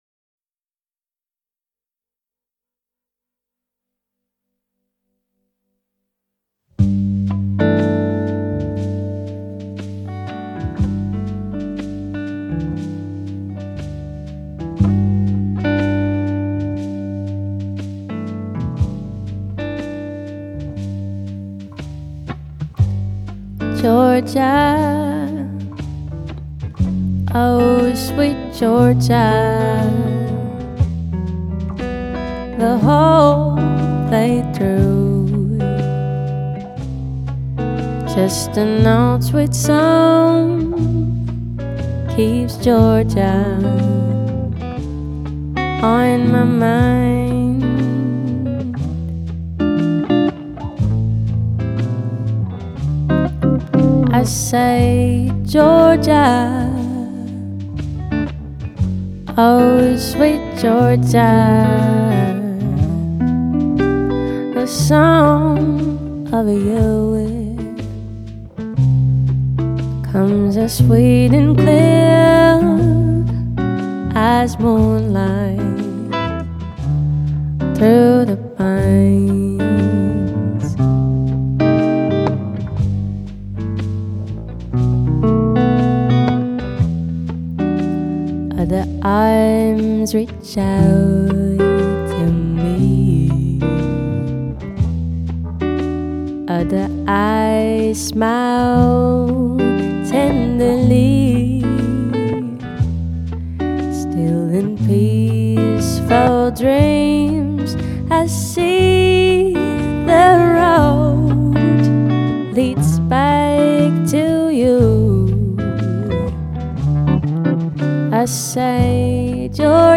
Jazz : l’élégance intemporelle